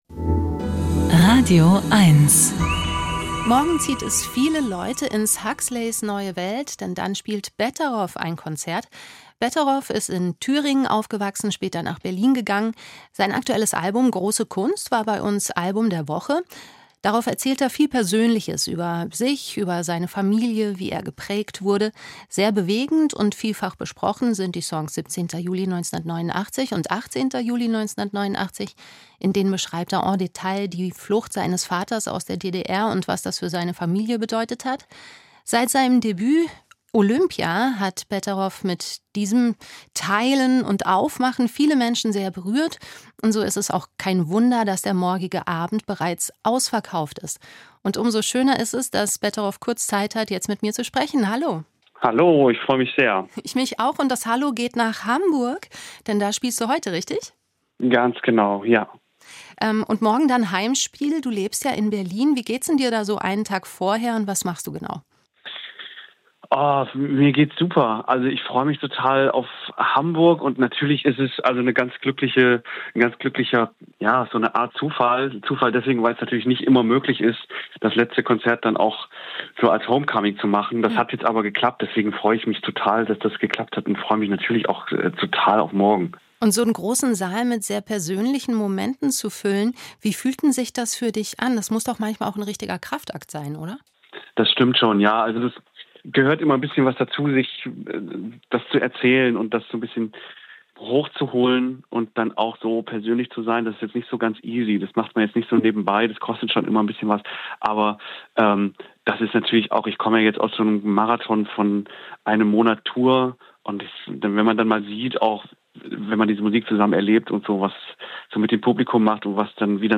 Musik-Interviews